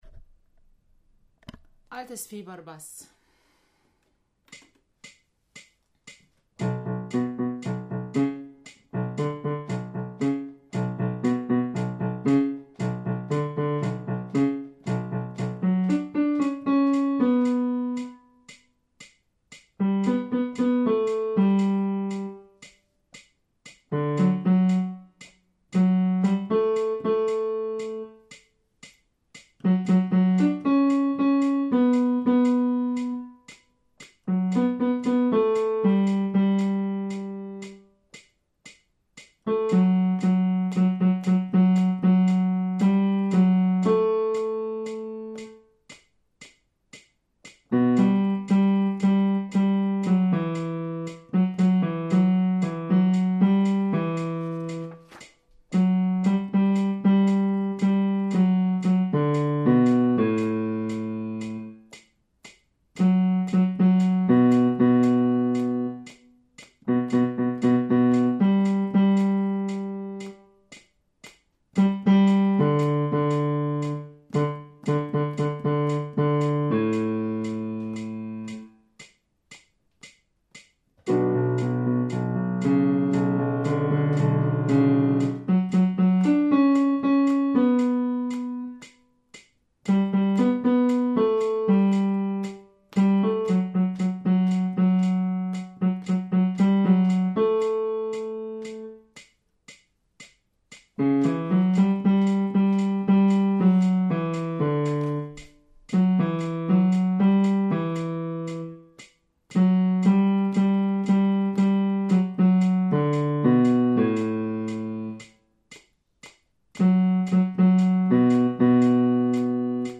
Altes Fieber – Bass